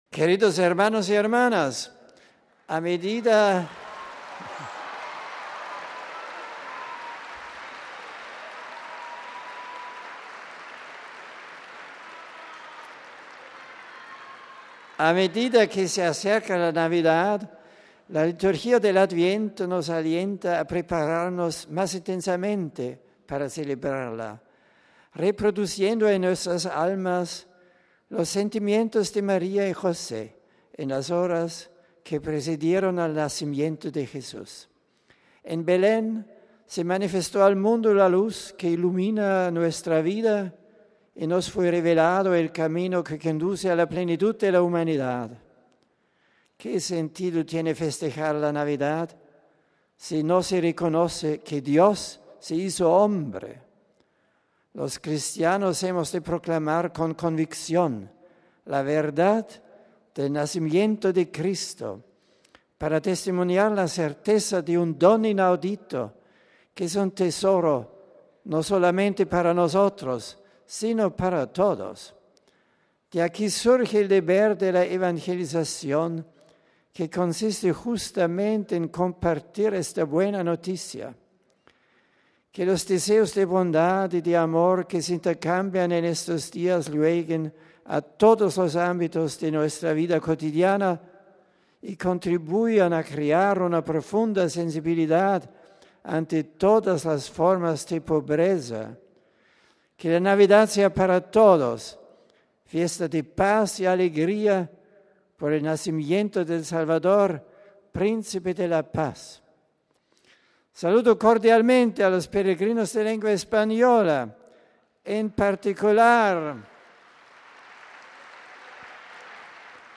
Este ha sido el resumen que en español ha hecho el Santo Padre para los fieles de nuestra lengua presentes en la Aula Pablo VI: RealAudio